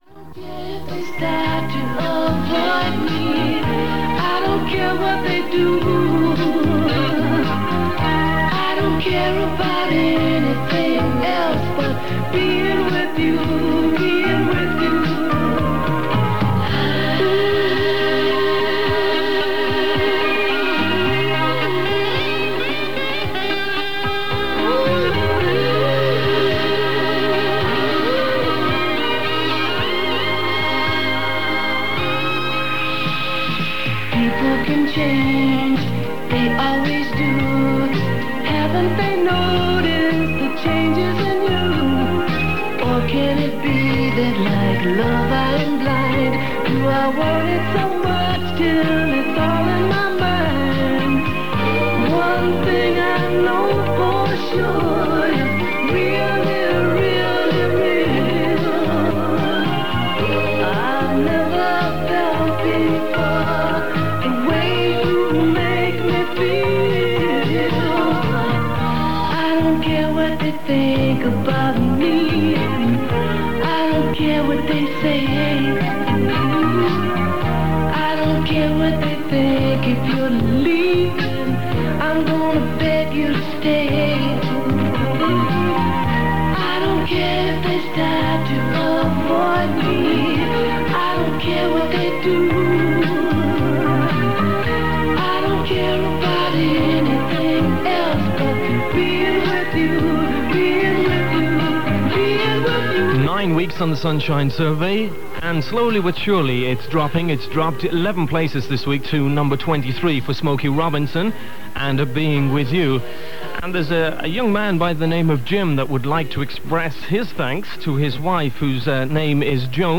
This is another recording of Dublin pirate Sunshine Radio during its first summer on air in 1981.
The tape was made from 531 kHz AM, announcing 539 metres, from 1933-2018 on Sunday 19th July.